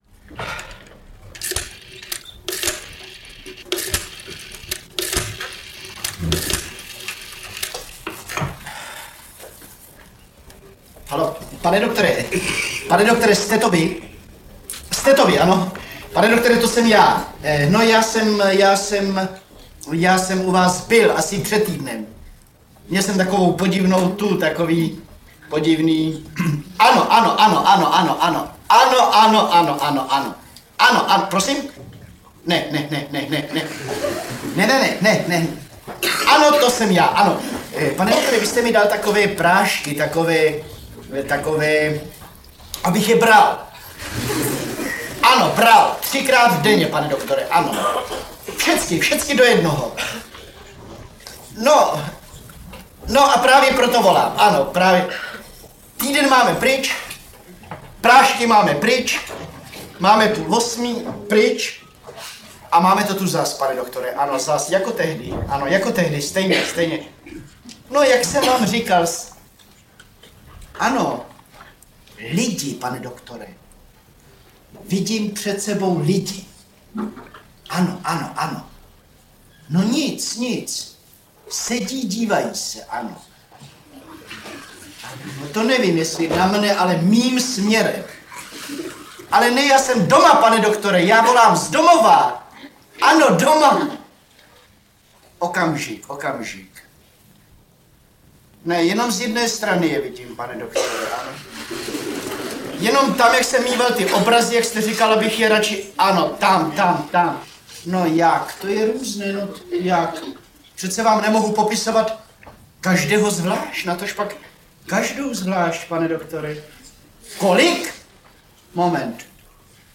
Živý záznam tohoto vystoupení